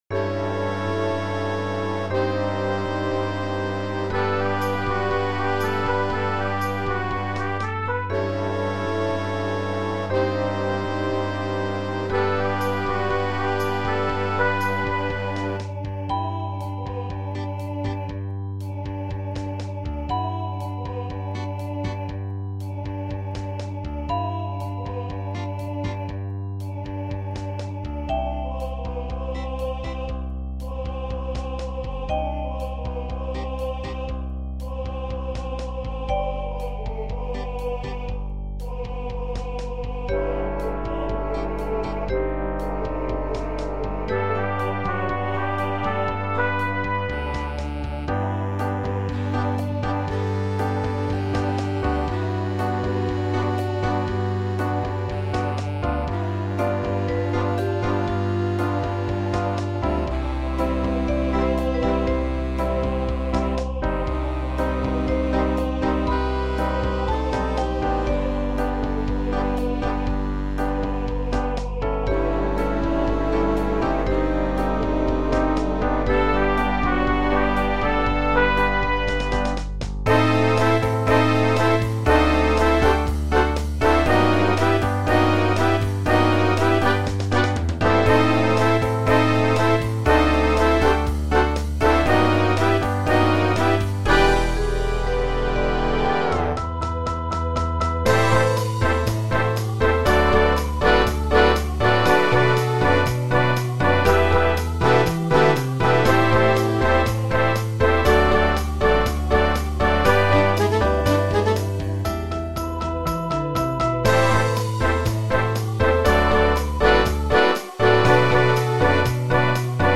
Jazz Band
Instrumentation is 5 saxes, 6 brass, 4 rhythm.